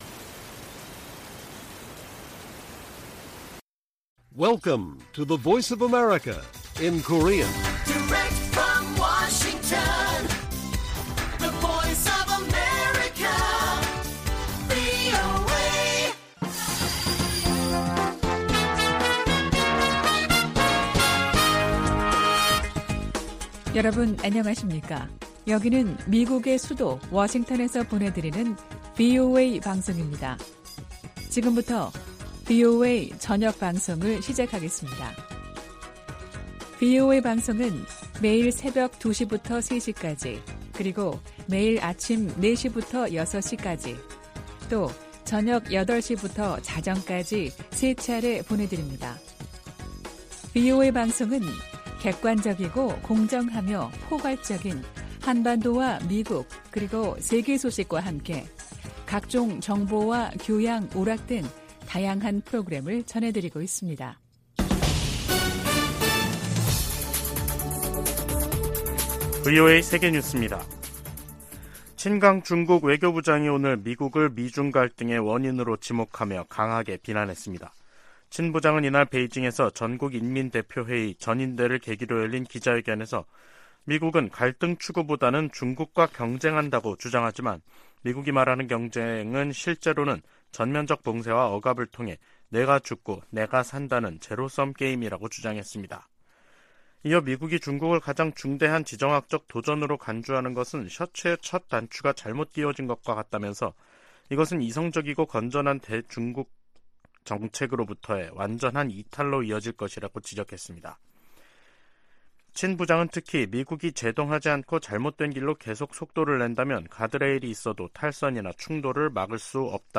VOA 한국어 간판 뉴스 프로그램 '뉴스 투데이', 2023년 3월 7일 1부 방송입니다. 북한이 이달 또는 다음달 신형 고체 대륙간탄도미사일(ICBM)이나 정찰위성을 발사할 가능성이 있다고 한국 국가정보원이 전망했습니다. 미 국무부는 강제징용 문제 해법에 대한 한일 간 합의를 환영한다는 입장을 밝혔습니다. 한국이 역사 문제 해법을 발표한 데 대해 일본도 수출규제 해제 등으로 적극 화답해야 한다고 미국 전문가들이 주문했습니다.